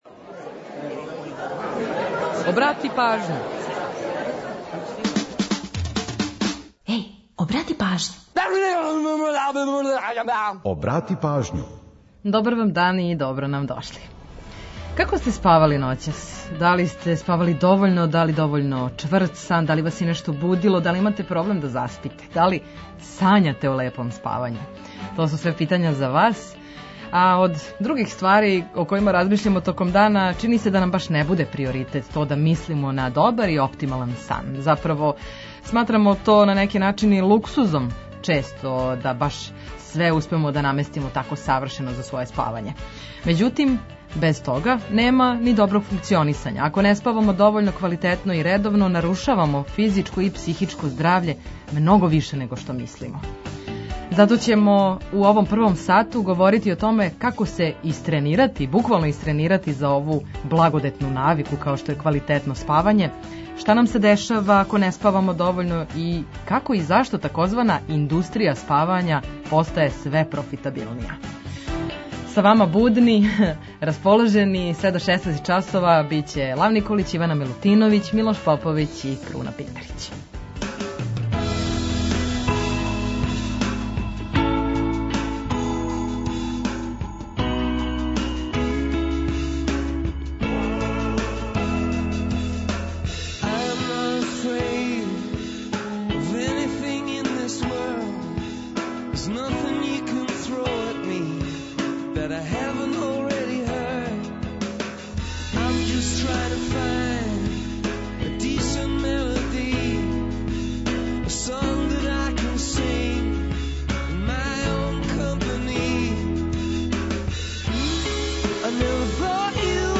Ту су и музичке теме којима подсећамо на приче иза песама и рођендане музичара и албума, уз пола сата резервисаних само за музику из Србије и региона.